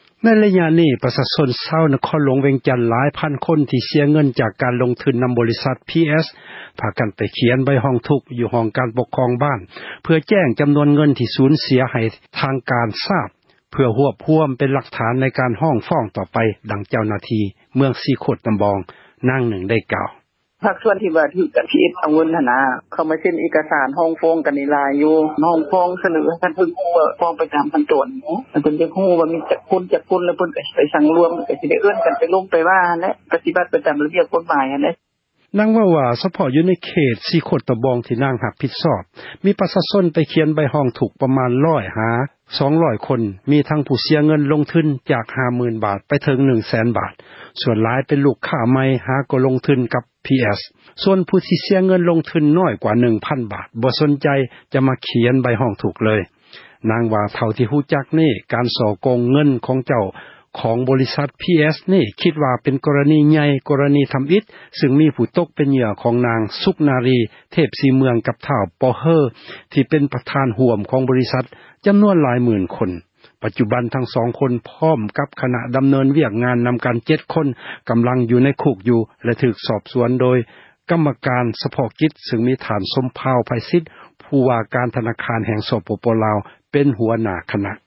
ດັ່ງເຈົ້າໜ້າທີ່ ເມືອງສີໂຄຕະບອງ ນາງນຶ່ງໄດ້ກ່າວວ່າ: